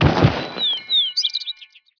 archer_impact.wav